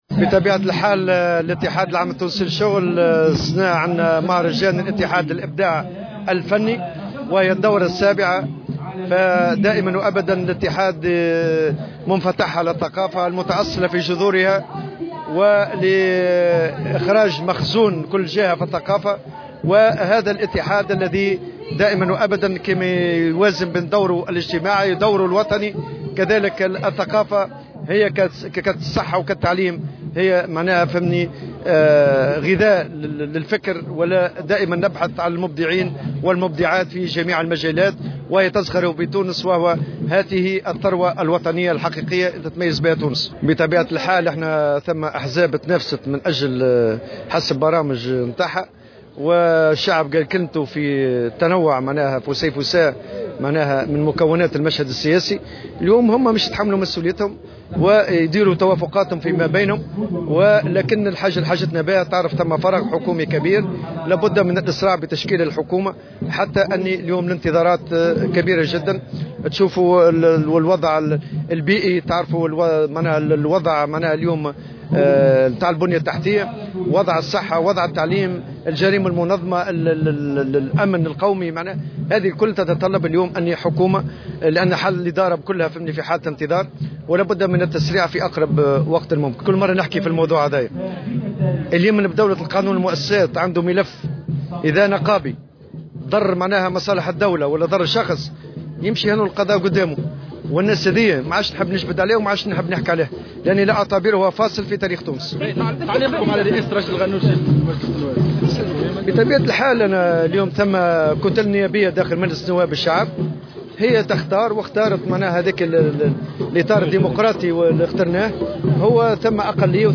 شدد الأمين العام للاتحاد العام اللتونسي للشغل، نور الدين الطبوبي، في تصريح لمراسل الجوهرة أف أم، على ضرورة الإسراع بتشكيل الحكومة الجديدة، داعيا مختلف الأطراف التي حظيت بثقة الناخبين إلى تحمل مسؤولياتها في هذا الإطار.
وأكد الطبوبي، على هامش مهرجان الاتحاد للإبداء الفني، اليوم الخميس في المنستير، أن انتظارات التونسيين كبيرة جدا، في ظل عديد الصعوبات التي يواجهونها على مستوى البنية التحتية والأمن والصحة والتعليم.